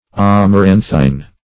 Amaranthine \Am`a*ran"thine\, a.